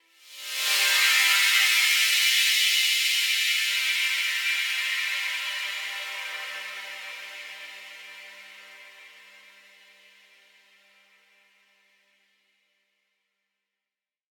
SaS_HiFilterPad02-C.wav